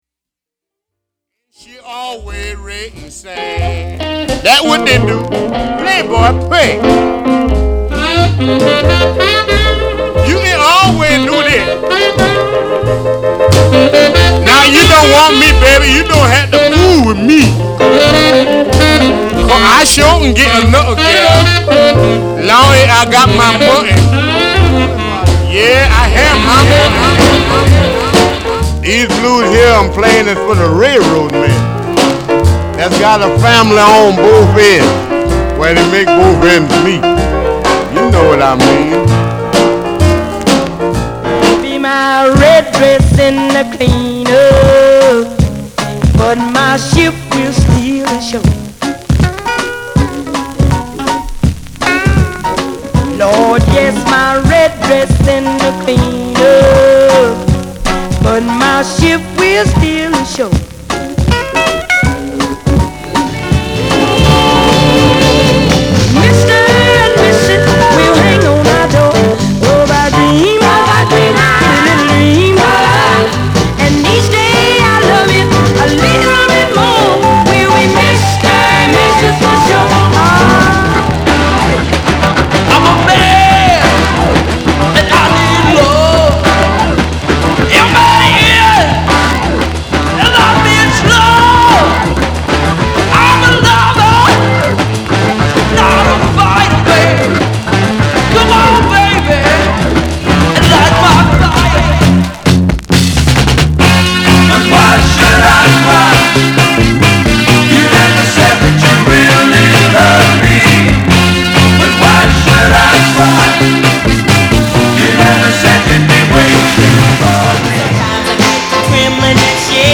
類別 藍調